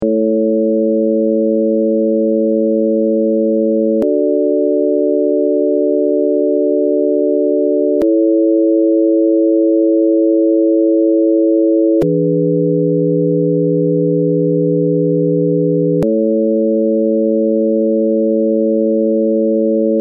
平均律カデンツァ
４声の合成は、電子的に２声+２声とし音響的に４声に合成する方法とする。
et_cadenza.mp3